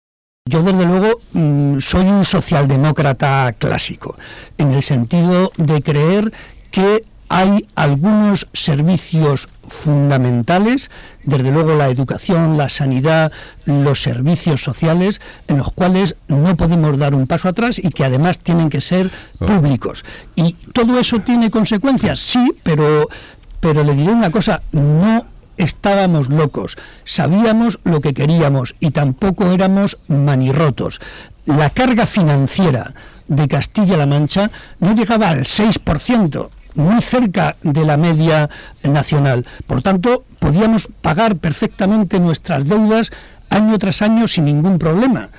El Presidente del Grupo Parlamentario y secretario general de los socialistas de Castilla-La Mancha, José María Barreda, ha realizado una entrevista en el programa radiofónico “Herrera en la Onda”, de Onda Cero.